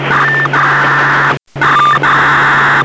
Lo standard ETSI 300-230 definisce un protocollo per la trasmissione di dati in FFSK (Fast Frequency Shift Keying), conosciuto come BIIS 1200 (Binary Interchange of Information and Signalling at 1200 bit/s) ed oggi piuttosto comunemente impiegato nelle comunicazioni radio civili in V/UHF.
Il layer fisico della comunicazione è costituito dall'alternarsi a 1200 bit per secondo di due note a 1200 ("1" digitale) e 1800Hz ("0" digitale), quindi niente di troppo esotico.